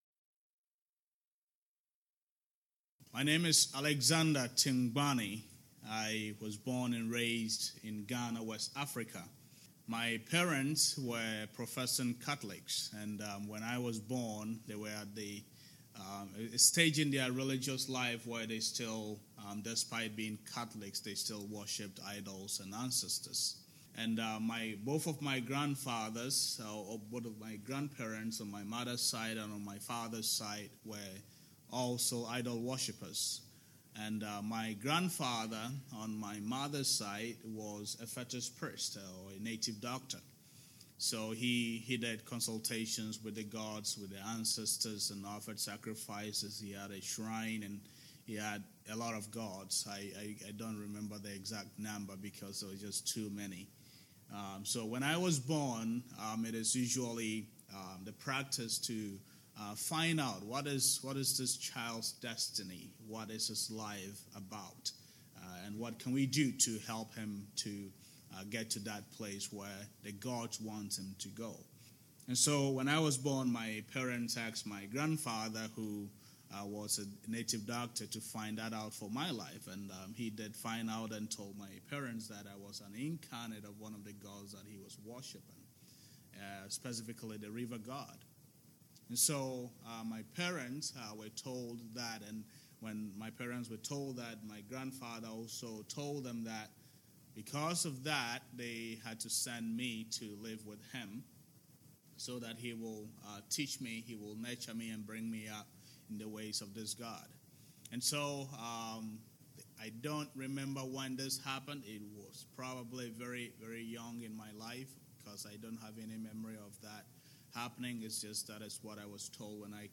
Sermons from visiting guests or other speakers